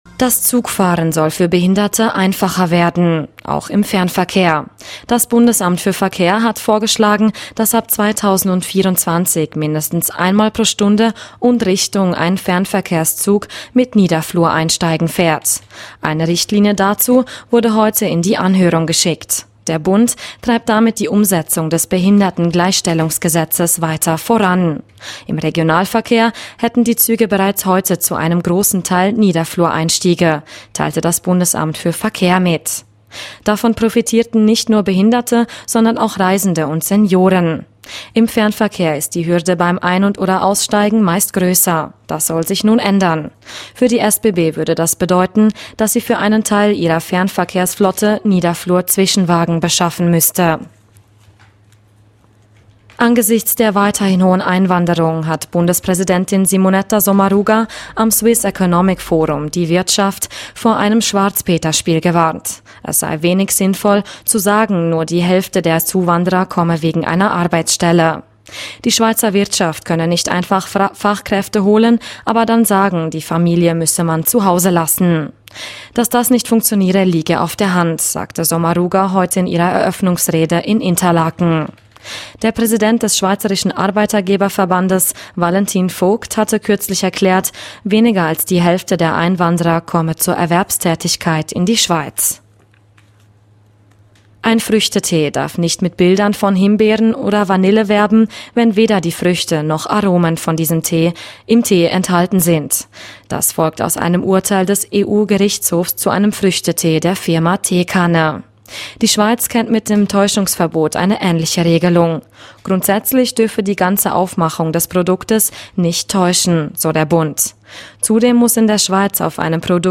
17:00 Uhr Nachrichten (1.87MB)